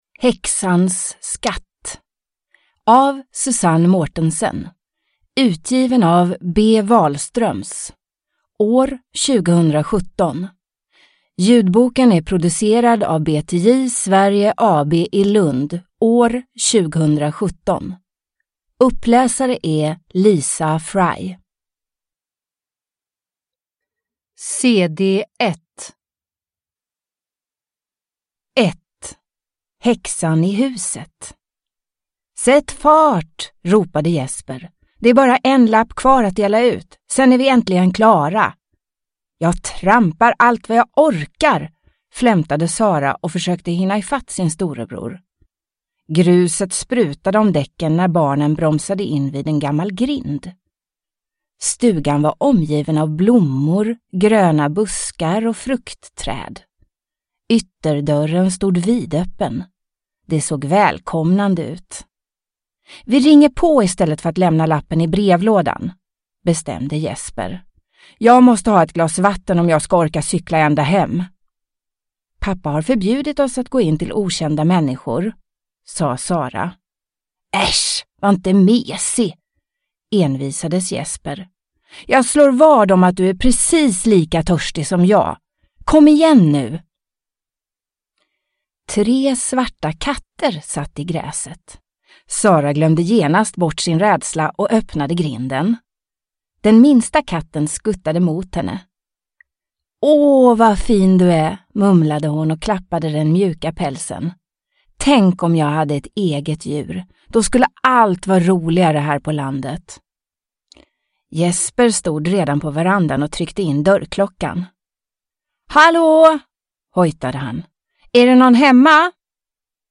Häxans skatt – Ljudbok – Laddas ner